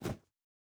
Jump Step Stone A.wav